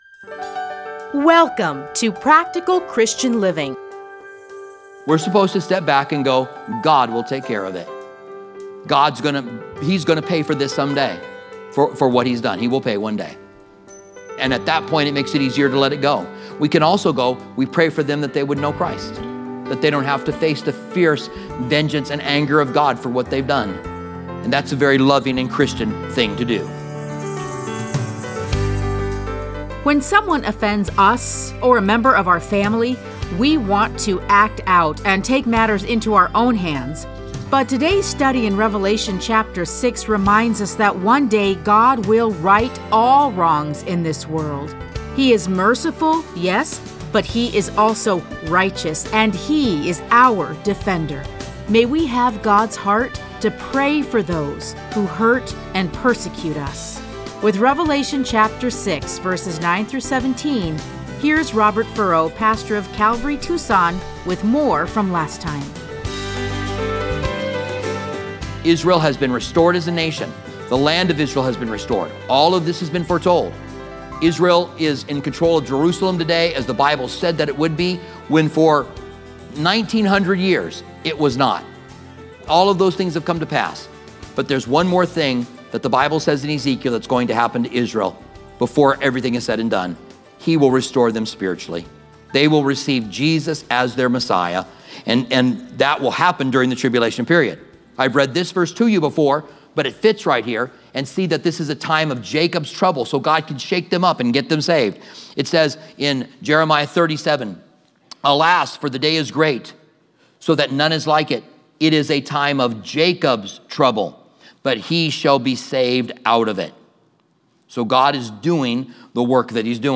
Listen to a teaching from Revelation 6:9-17.